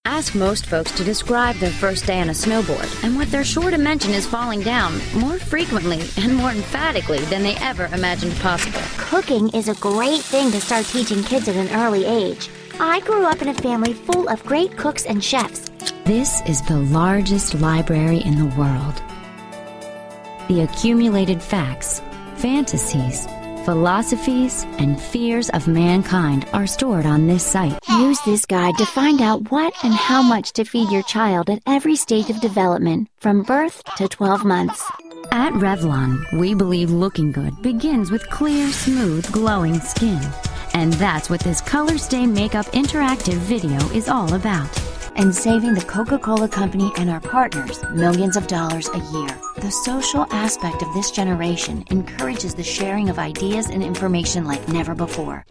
friendly, warm, sophisticated, inviting, breezy, tv spots, radio spots, elearning
mid-atlantic
Sprechprobe: Industrie (Muttersprache):